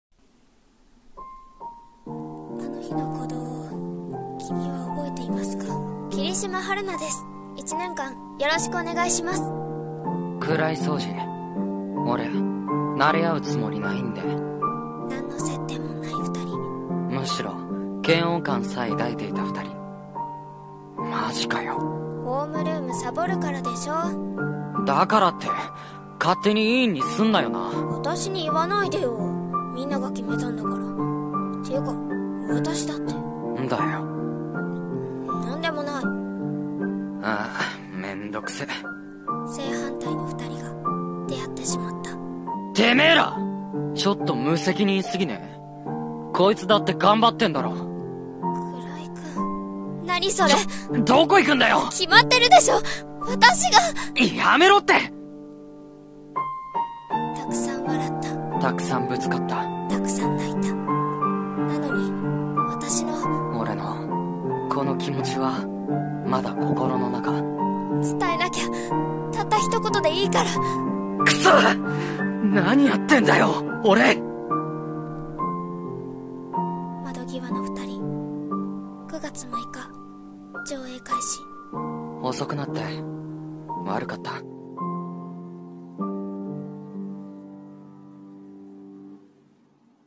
「声劇台本」アニメ映画予告風